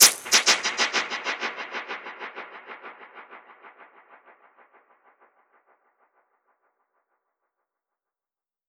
DPFX_PercHit_C_95-05.wav